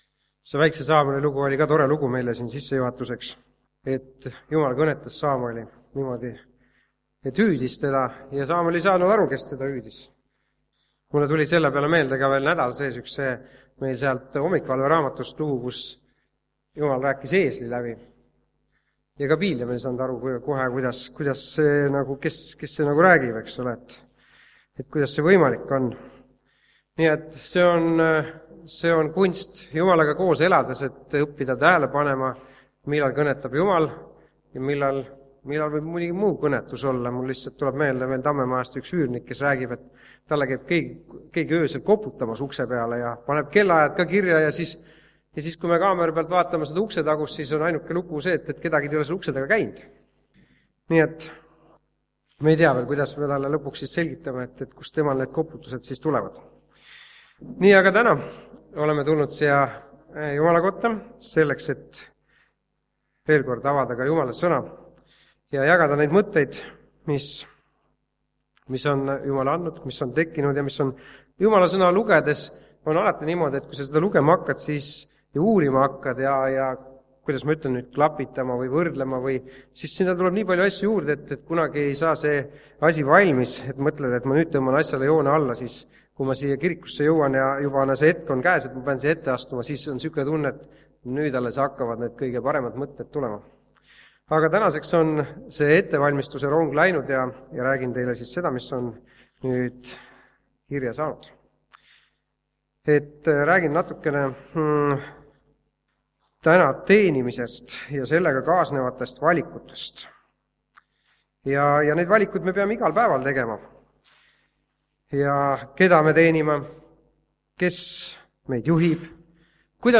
Jutlused